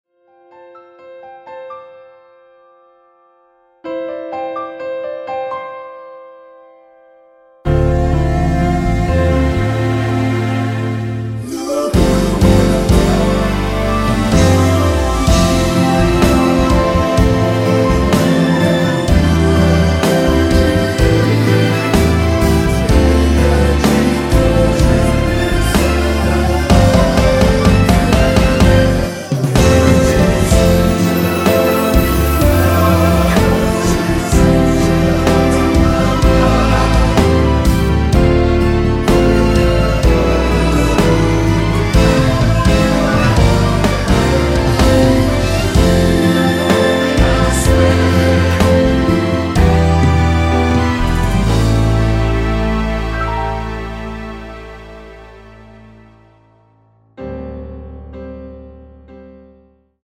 (-2)내린 코러스 포함된 MR 입니다.(미리듣기 참조)
앞부분30초, 뒷부분30초씩 편집해서 올려 드리고 있습니다.
중간에 음이 끈어지고 다시 나오는 이유는